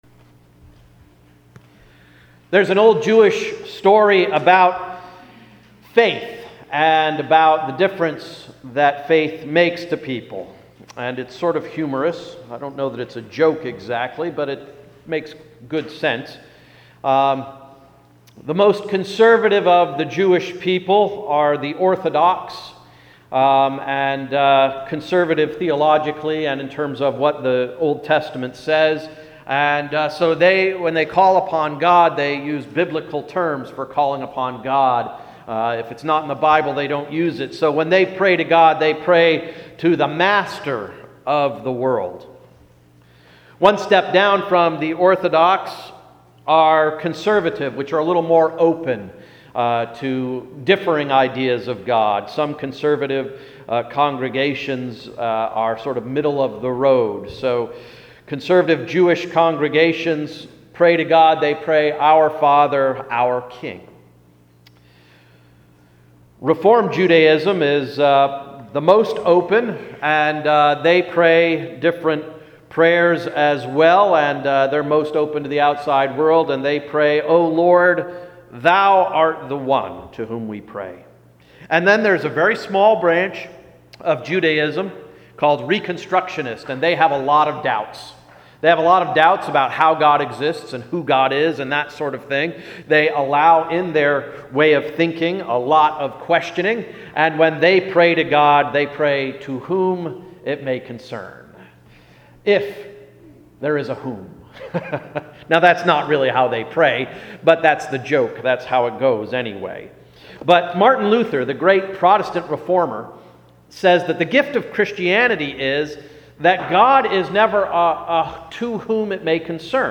Sermon of May 25, 2014–“To Whom It May Concern” – Emmanuel Reformed Church of the United Church of Christ